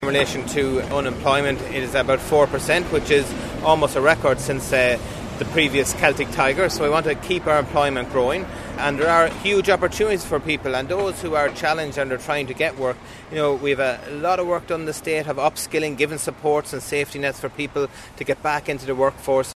Minister Peter Burke, says upskilling programs are available for those in long-term unemployment……………